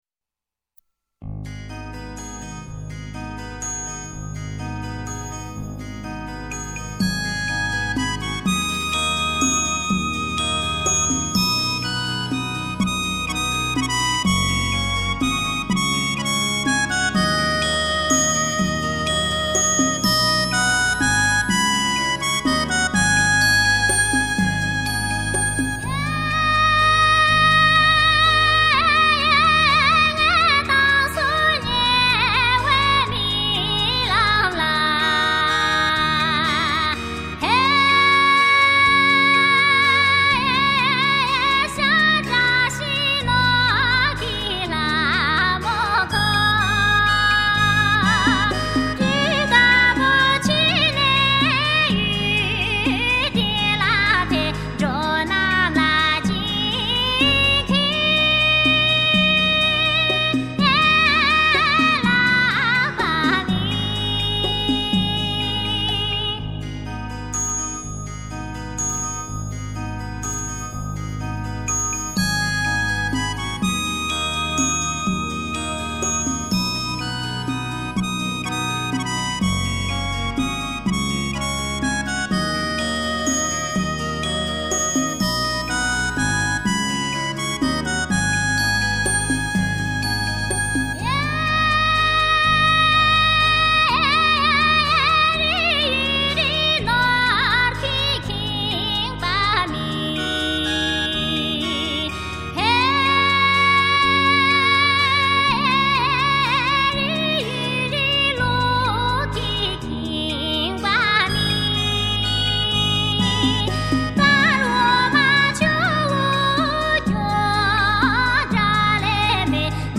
[05--20]西藏民歌(梦)128kb